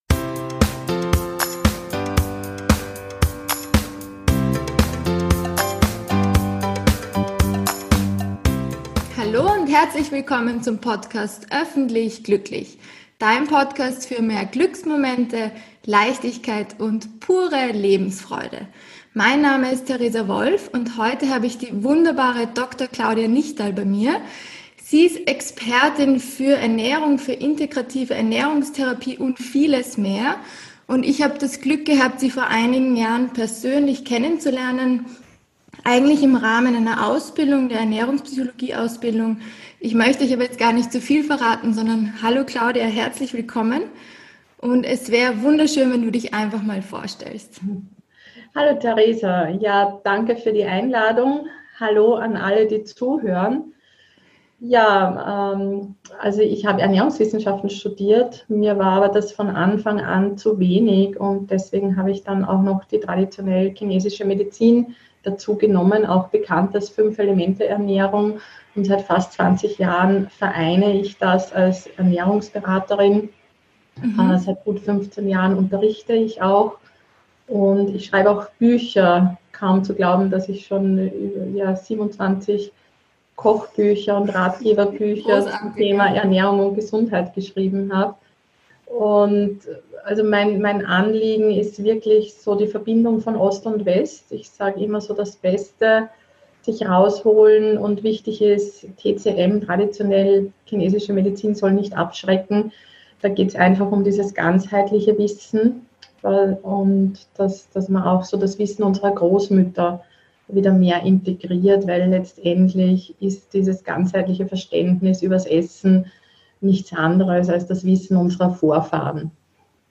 #22 - [Interview] Wie du dein Wohlbefinden durch die richtige Ernährung im Sommer stärkst ~ Öffentlich Glücklich Podcast